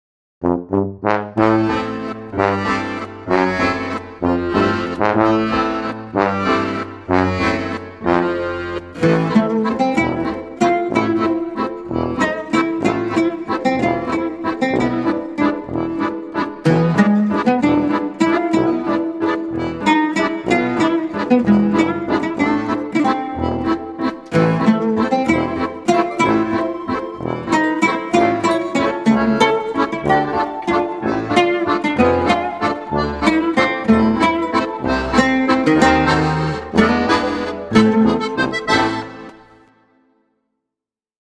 Musikalische Lesung mit dem Münchener Autor und Regisseur Su Turhan und Duetto.